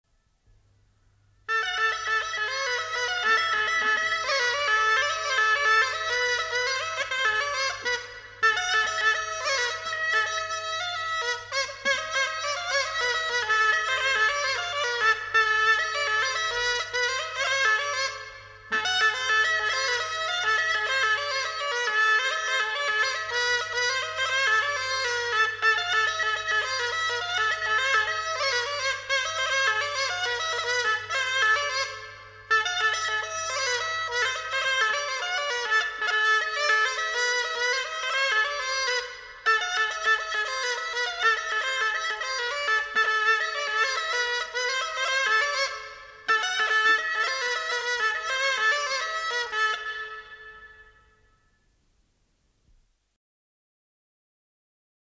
Голоса уходящего века (Курское село Илёк) Тимоня (рожок, инструментальный наигрыш)